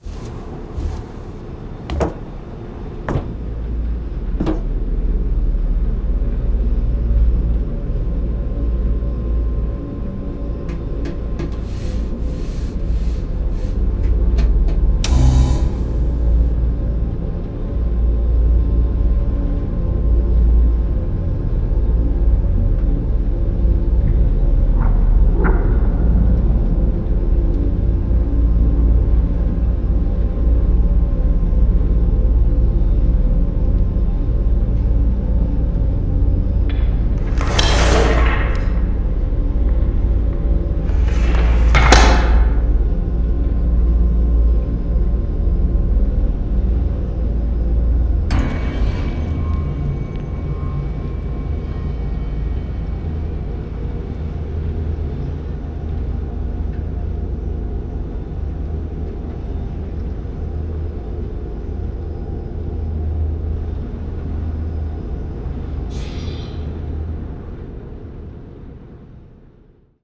Each clip begins with the sound of your footsteps entering the core chamber (via stepping off the Titan's hand), then shortly afterwards you'll hear the sound of you pet-patting the inside of the core chamber, followed by the Titan's reaction.
These were mostly put together with royalty-free sound effects (from Freesound and FilmCow's pack), and a few sounds were recorded by me! The initial footsteps (before they step onto the metallic surface) are me walking in socks on a doormat. The core chamber pet-pat sounds are me petting an oven tray.
Titan TV core ambience (plain file link)
• Reaction to being petted: degausses screen.
• Other sounds: extends then retracts the claw arms on their back. Plus general robotic drone sounds.
• laser gun
• metal device extended
• sci fi bell